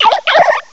cry_not_froakie.aif